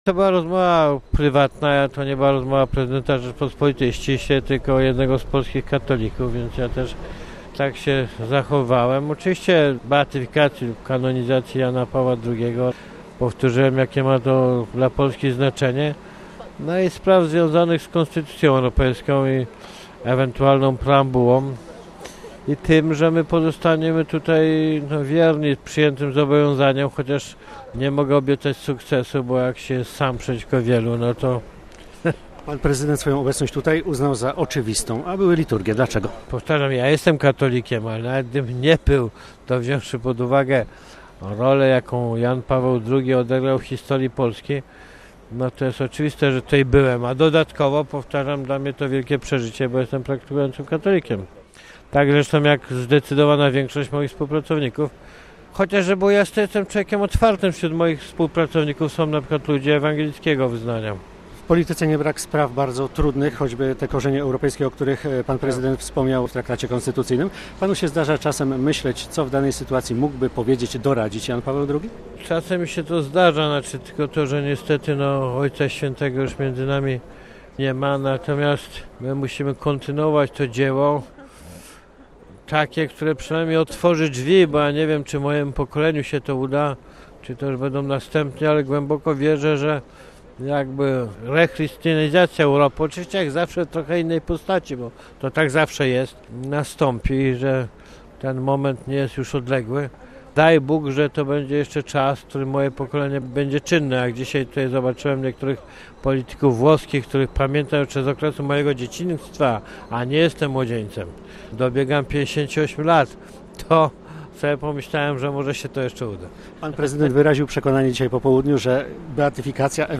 Audiencja dla Lecha Kaczyńskiego – wywiad z prezydentem
Prywatna rozmowa z Papieżem dotyczyła beatyfikacji Jana Pawła II oraz kwestii europejskich. W rozmowie z Radiem Watykańskim Lech Kaczyński podkreślił, że przybył do Watykanu przede wszystkim jako człowiek wierzący.